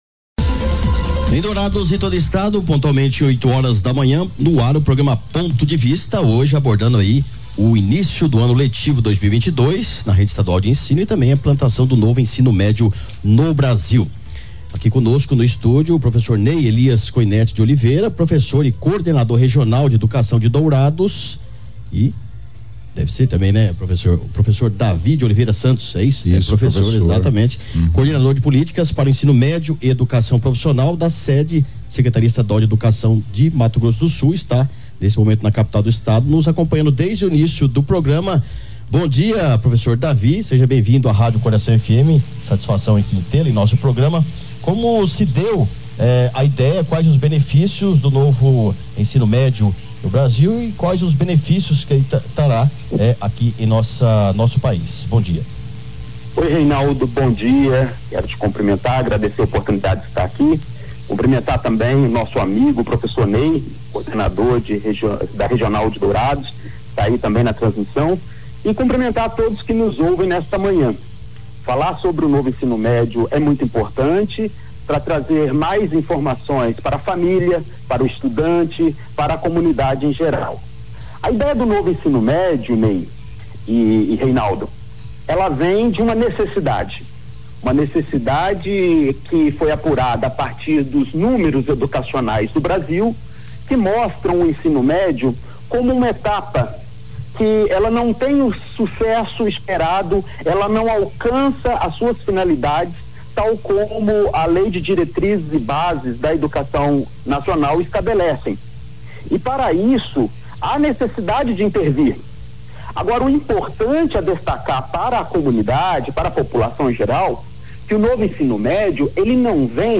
por telefone de Campo Grande/MS.